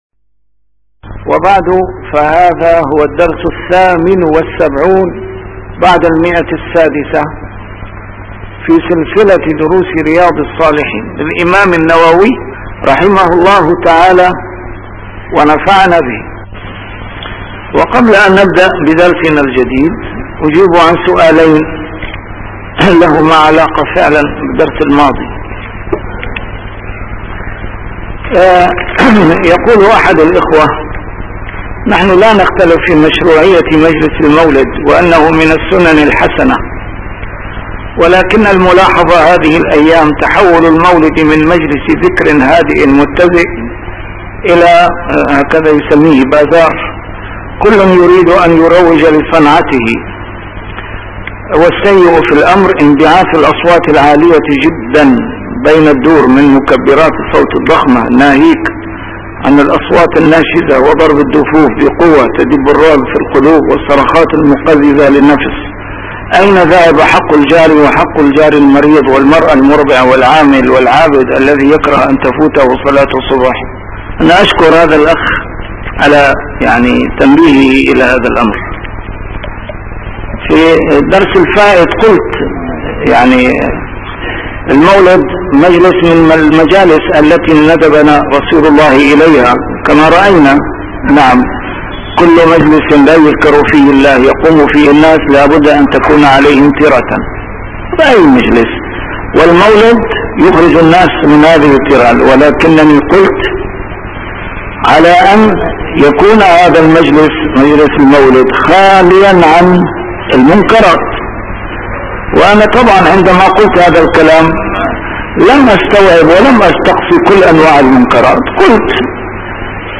A MARTYR SCHOLAR: IMAM MUHAMMAD SAEED RAMADAN AL-BOUTI - الدروس العلمية - شرح كتاب رياض الصالحين - 678- شرح رياض الصالحين: الرؤيا